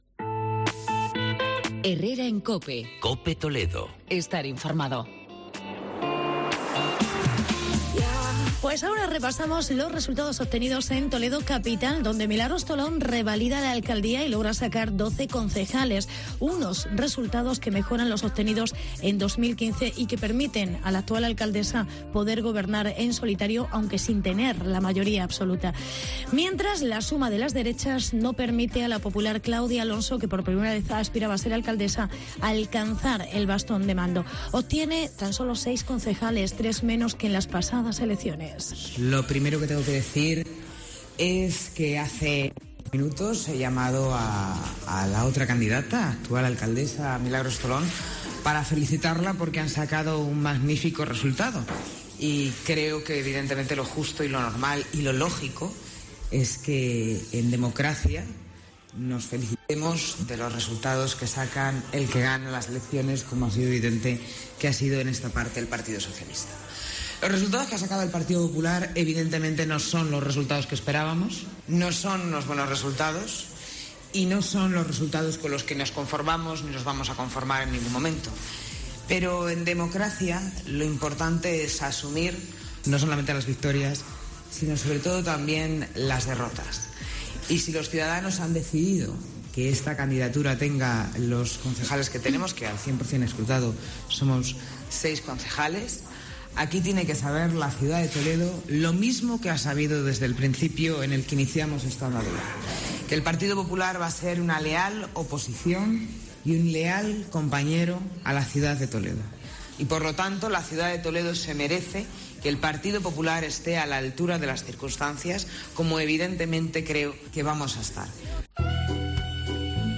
AUDIO: Claudia Alonso sólo consigue 6 concejales. Reportaje sobre las elecciones en Toledo